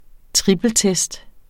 Udtale [ ˈtʁibəlˌtεsd ]